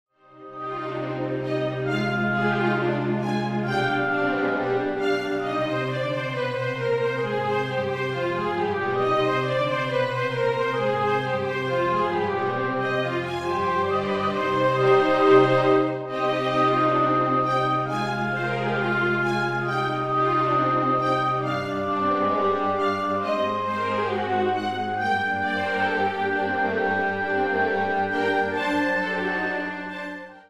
Kammerorchester-Sound